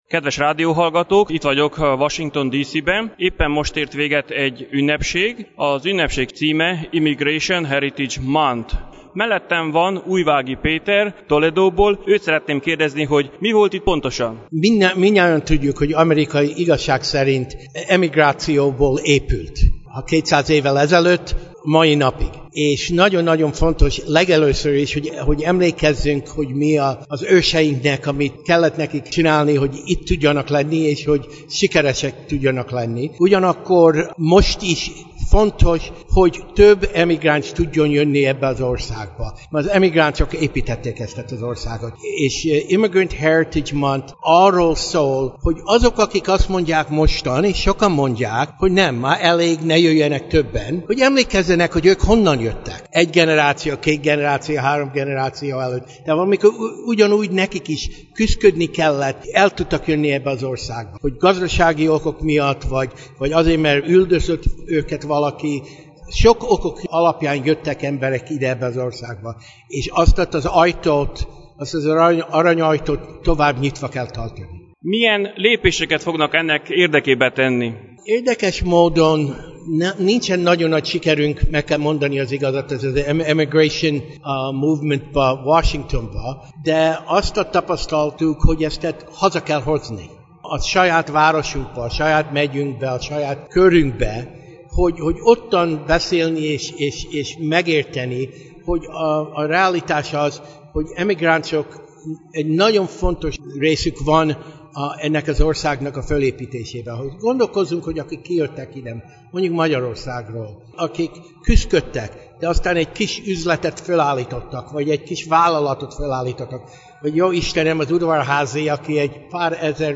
Június 1-én, az ünnepi hónap első napján a capitoliumi domb egyik épületében több mint 200 résztvevő előtt több kongresszusi képviselő és politikus megszólalt, tisztelegve az amerikai bevándorlók előtt, elismerve, hogy Amerika egyedülisége és nagyszerűsége a bevándorlóknak is köszönhető.
A magyar delegációból a toledoi Újvági Péter szólalt fel, elmondta bevándorlásának történetét majd kihangsúlyozta, hogy az emigráció továbbra is kell folytatódjon, hogy Amerika maradjon a fejlődés útján, befogadva a vállalkozó és az amerikai álmot éltető emigránsokat.